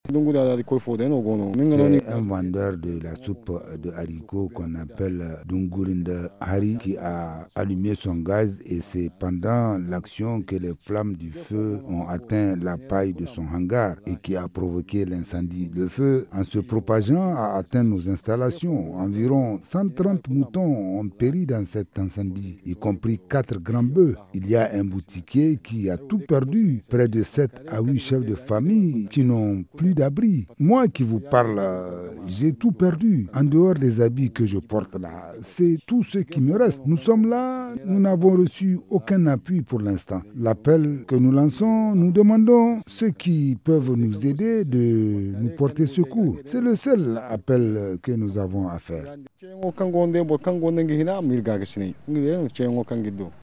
Reportage de l’ incendie dans la ceinture verte